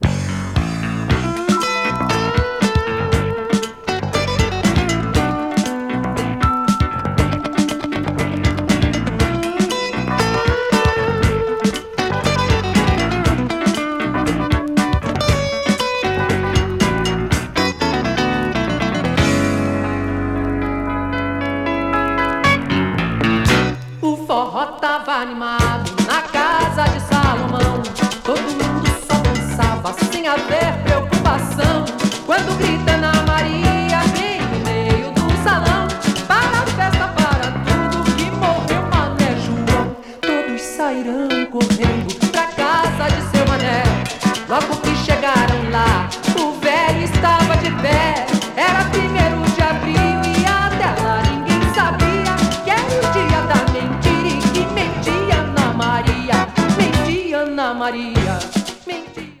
80s FUNKY LATIN / BRASIL MELLOW 詳細を表示する